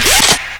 Added all the sounds needed for the remaining weapons.
Selectin.wav